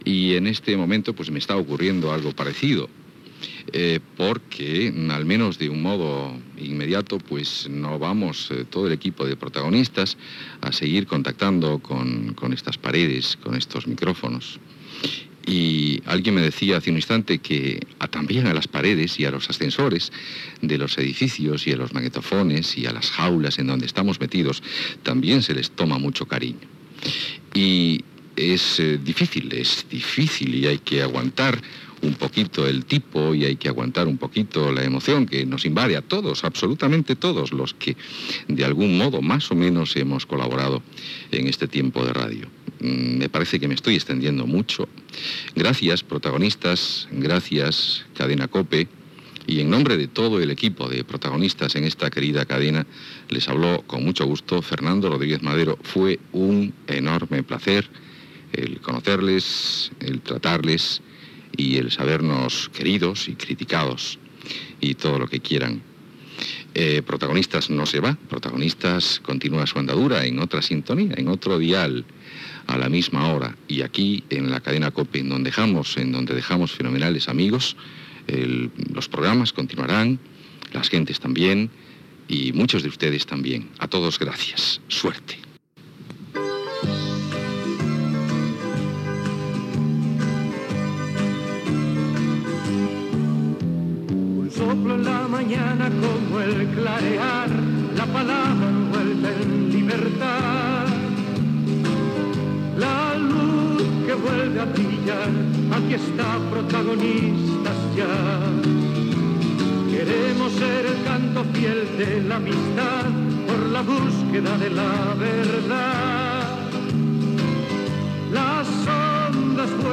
Comiat del programa "Protagonistas" de la Cadena COPE, cançó del programa
Info-entreteniment
FM